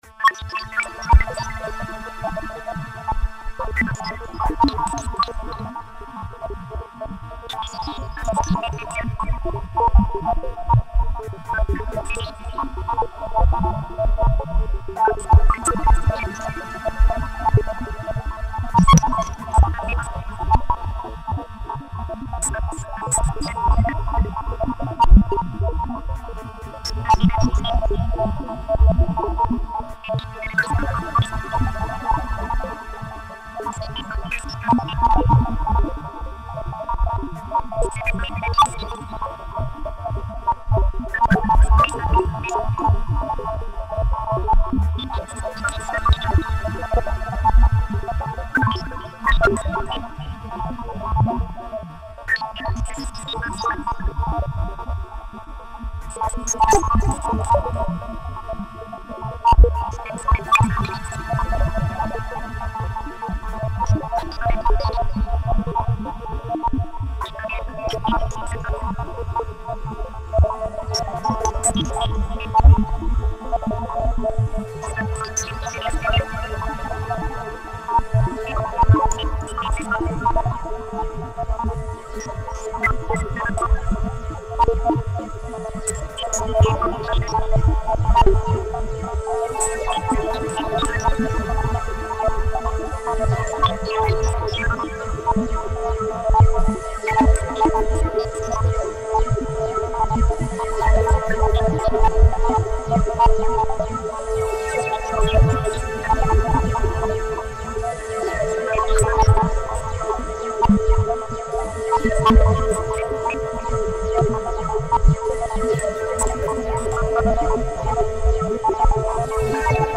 File under: Avantgarde
shifting around beats and rhythmic textures